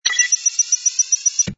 hud_zoom_in.wav